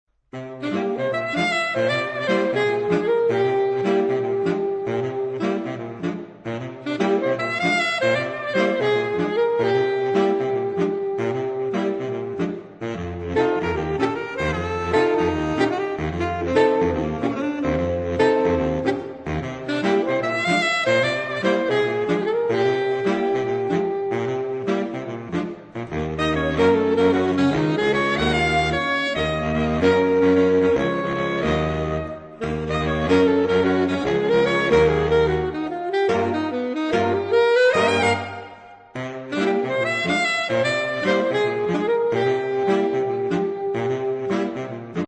4 Saxophone (SATBar/AATBar) Obtížnost